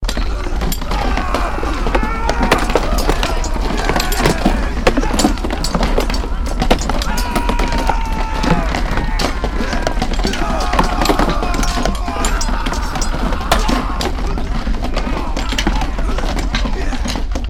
swordfight-yells-1.ogg